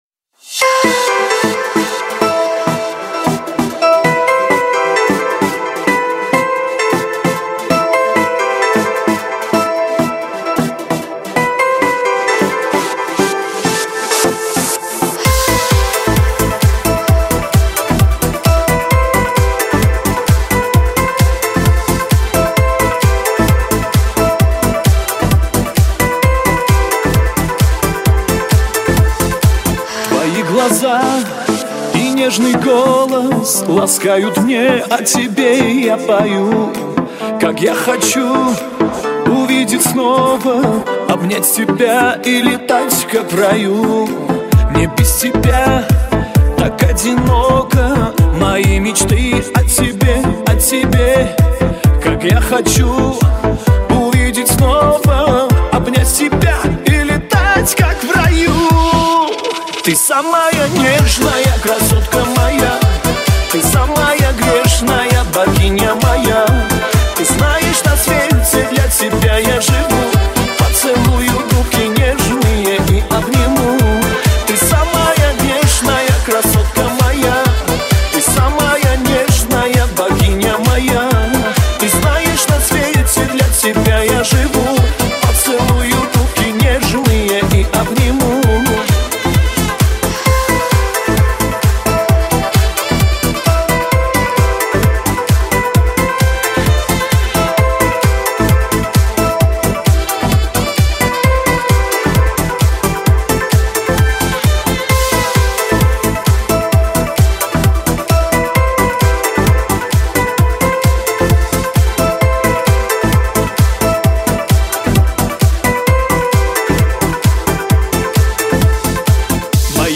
Erger 2017, Армянская музыка